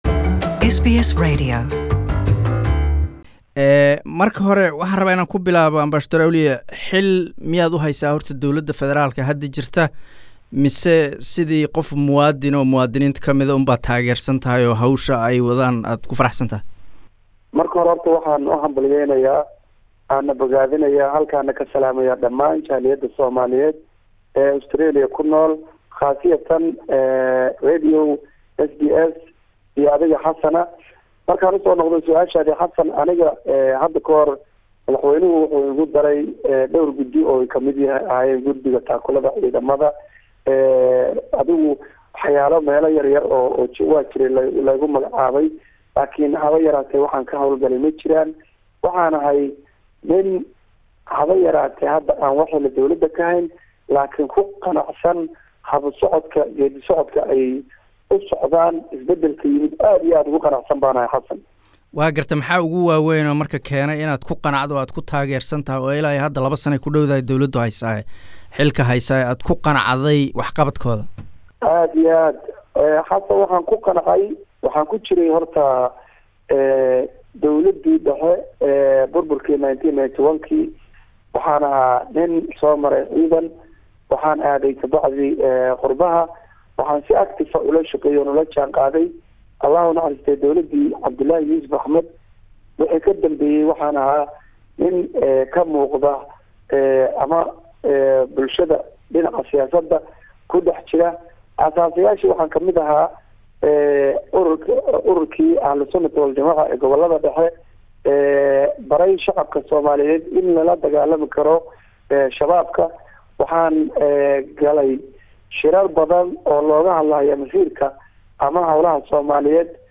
Waraysi: Ambassador Awliyo